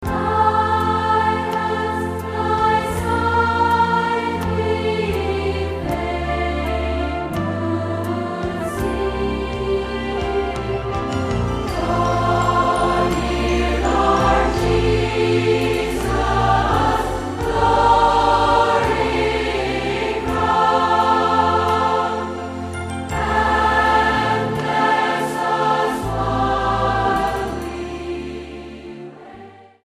STYLE: MOR / Soft Pop
300 singers from over 90 churches in Central Scotland